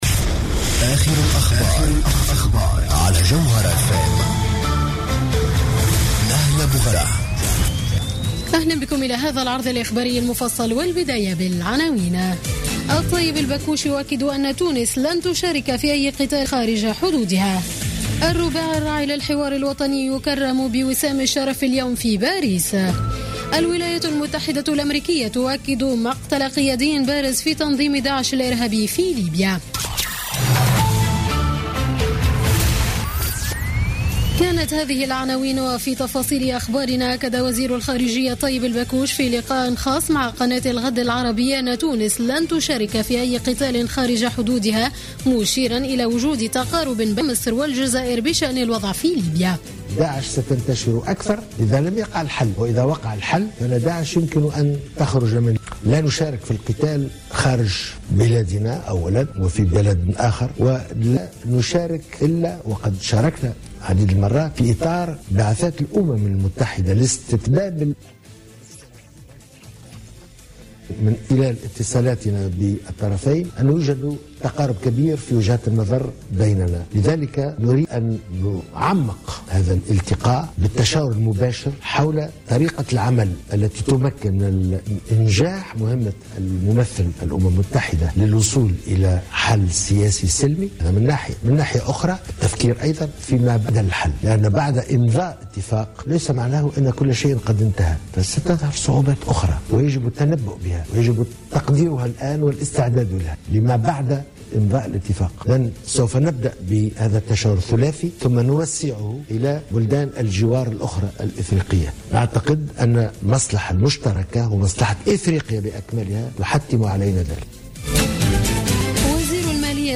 نشرة أخبار منتصف الليل ليوم الثلاثاء 8 ديسمبر 2015